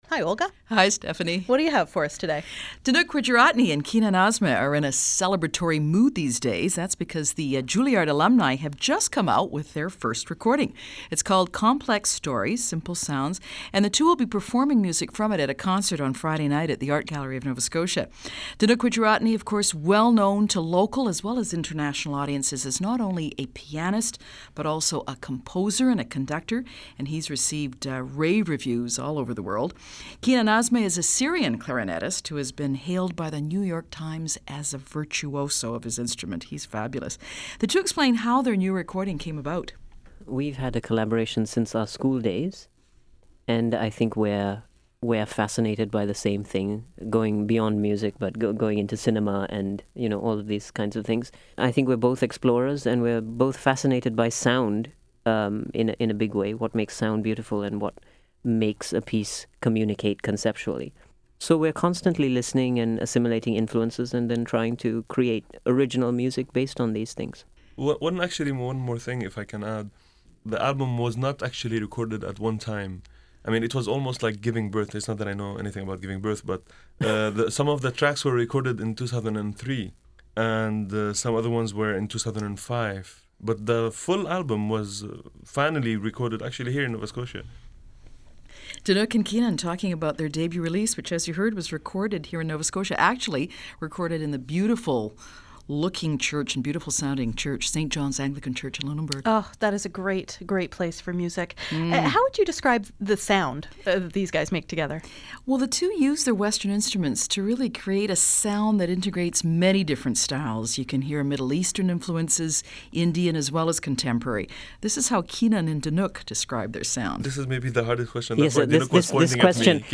– CBC interview 2009:
CBC-interview-2009.mp3